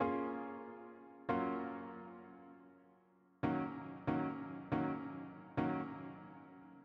描述：另一个Rnb Loop是给你们的，我希望能用得上
标签： 140 bpm RnB Loops Harp Loops 2.31 MB wav Key : Fm FL Studio
声道立体声